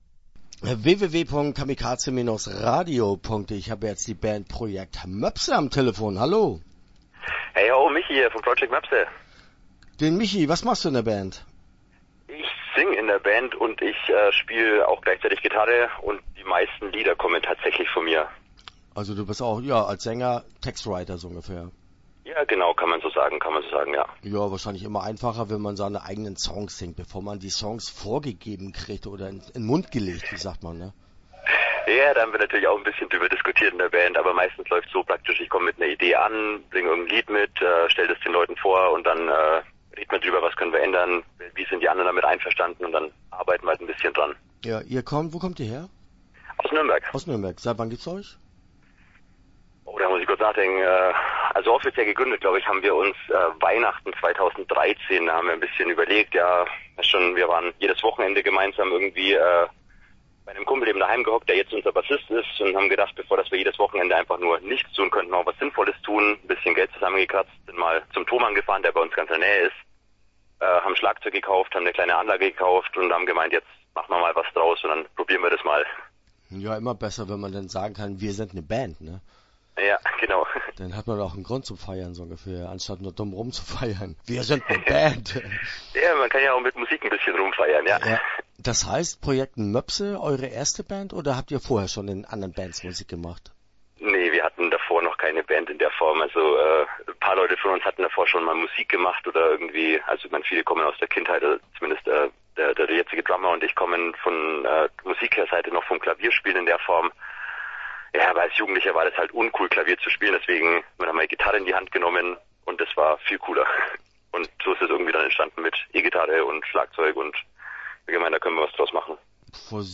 Project Moepse - Interview Teil 1 (10:42)